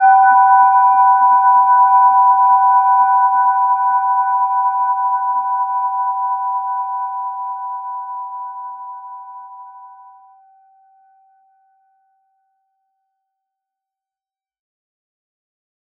Gentle-Metallic-2-B5-p.wav